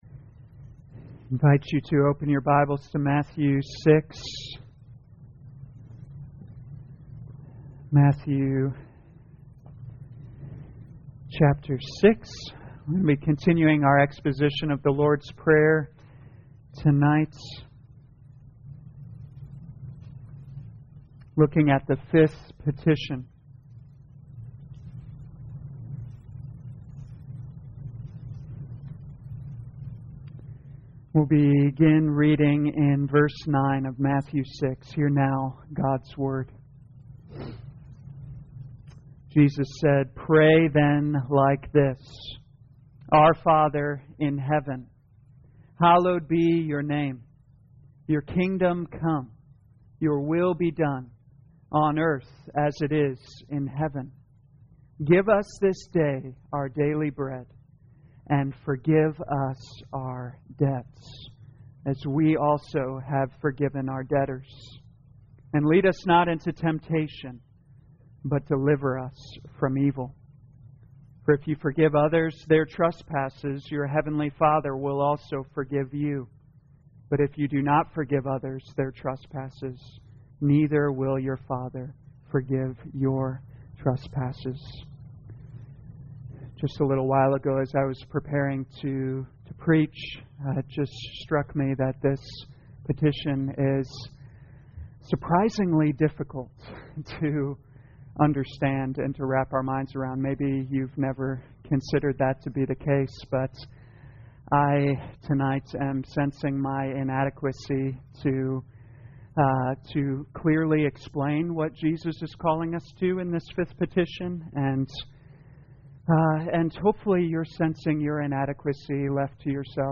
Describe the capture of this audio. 2021 Matthew Prayer Evening Service Download